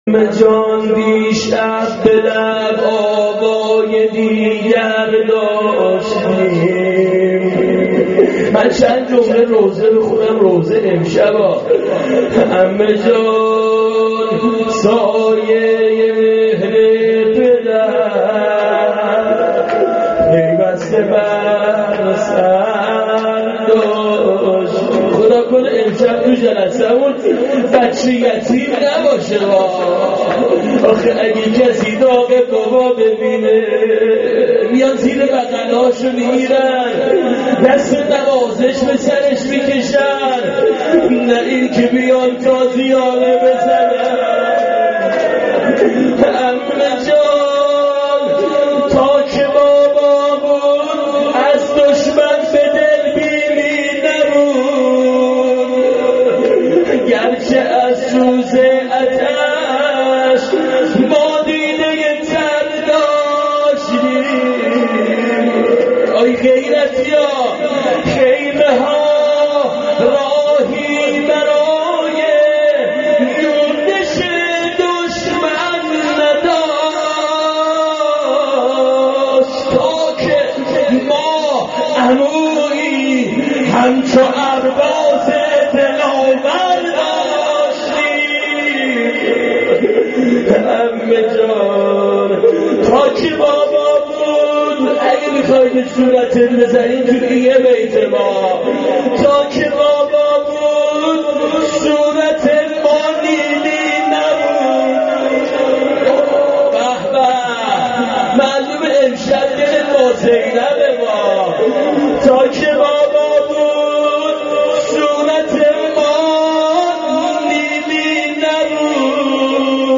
عمه جان دیشب به لب آوای دیگر داشتیم------روضه شام غریبان.MP3